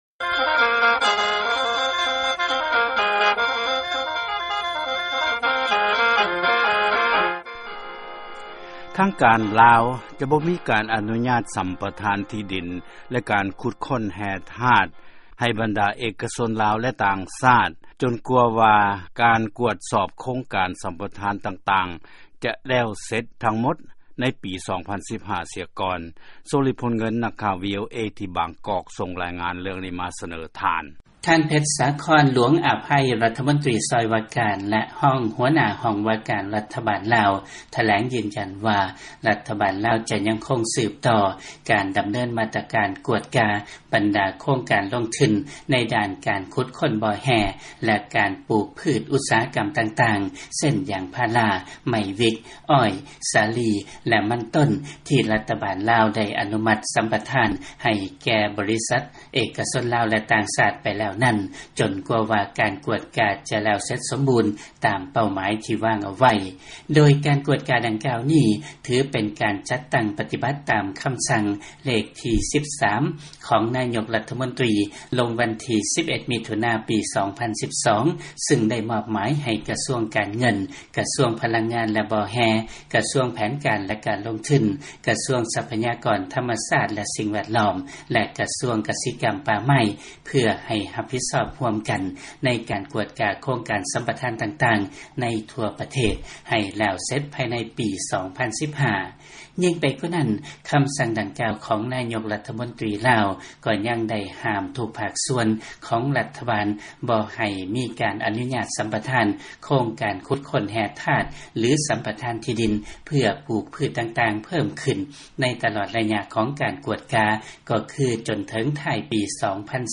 ຟັງລາຍງານ ລາວຈະບໍ່ອະນຸຍາດ ສຳປະທານ ຂຸດຄົ້ນແຮ່ທາດ ແກ່ ບໍລິສັດທັງຫຼາຍ ຈົນກວ່າປີ 2015.